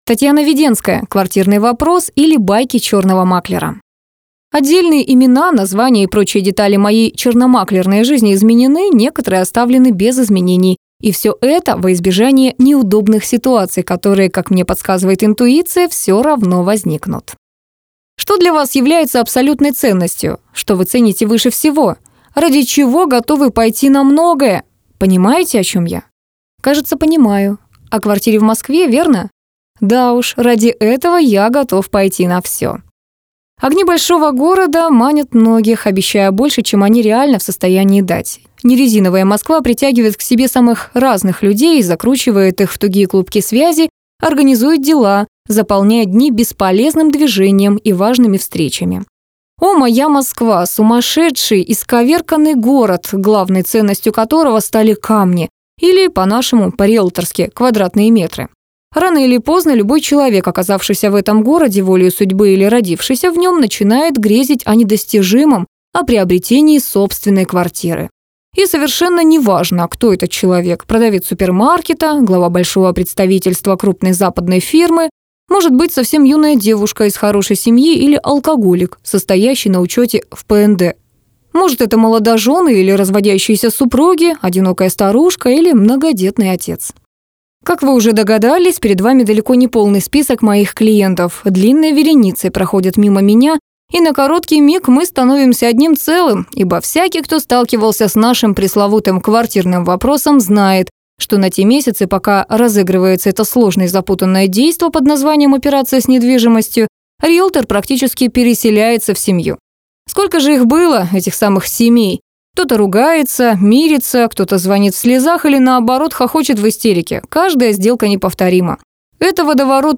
Аудиокнига Квартирный вопрос, или Байки черного маклера | Библиотека аудиокниг